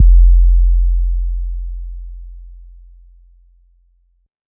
SOUTHSIDE_808_cleanhood_F.wav